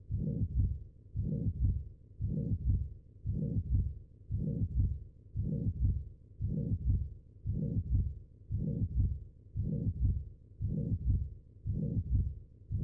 Mitral regurgitation. Acute valvular dysfunction./Flash pulmonary edema Emergency Valvular surgery